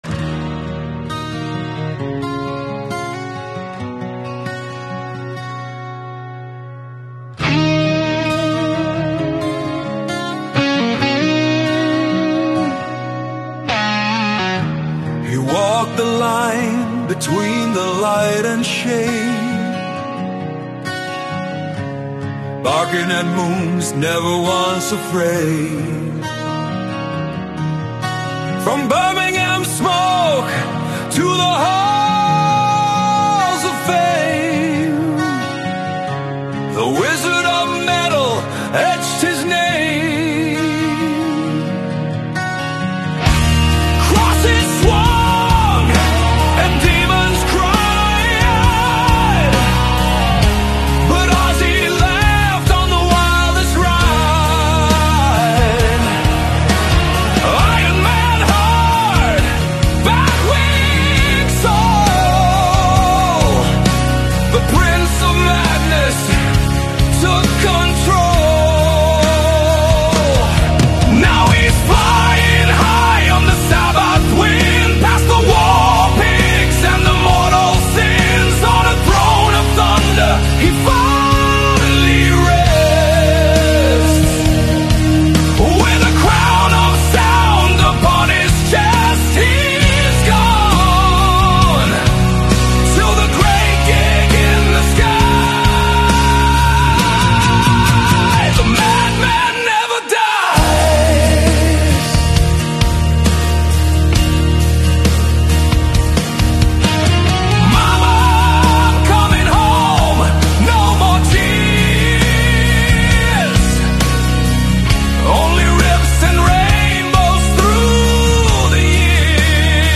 Sad Ballad
Original Sad Tribute Ballad